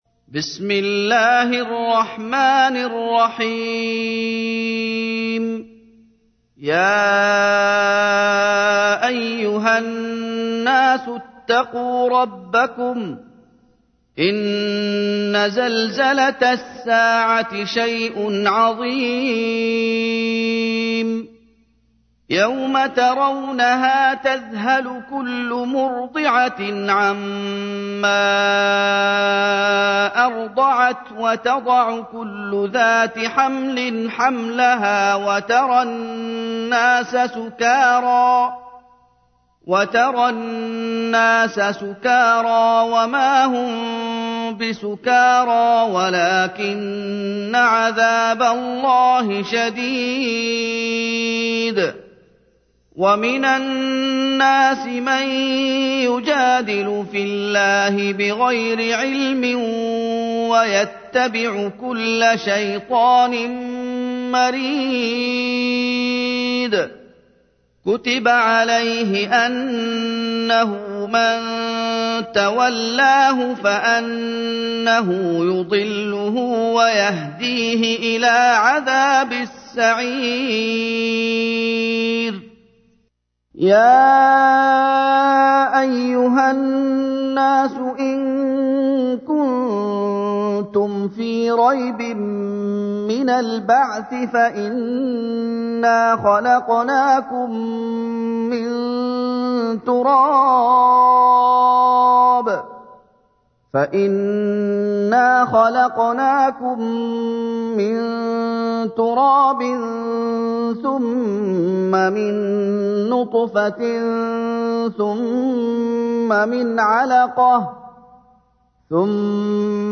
تحميل : 22. سورة الحج / القارئ محمد أيوب / القرآن الكريم / موقع يا حسين